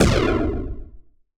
boom0.wav